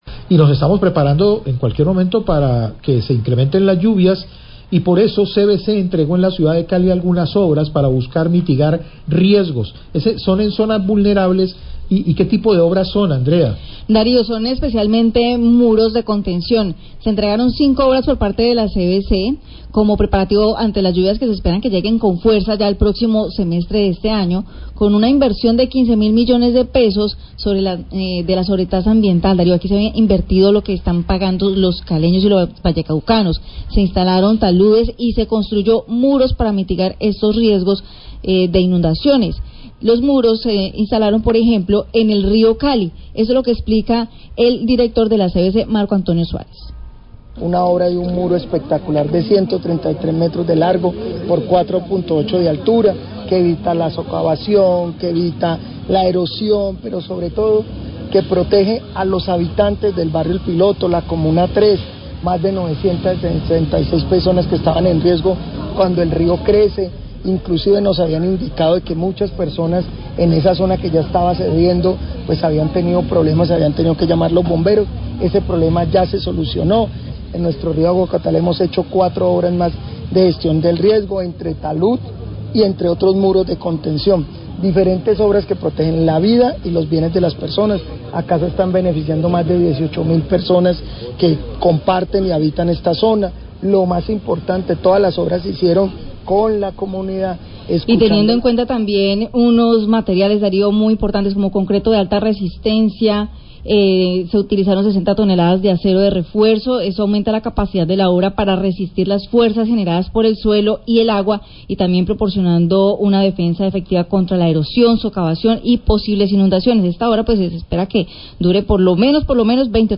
Radio
Con una inversión cercana a los 15 mil millones de pesos, recursos provenientes de la sobretasa ambiental, la CVC entregó las obras de 5 muros de contencion para el Río Cali y el Río Aguacatal, con lo que se mitigará los riesgos de inundaciones y erosión de orilla cuando se presneten las lluvias. Declaraciones del director  generald e la CVC, Marco Antonio Suárez.